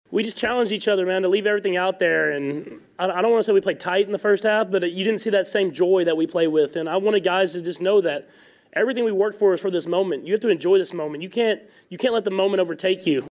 Chiefs quarterback Patrick Mahomes what was said at halftime :13 sec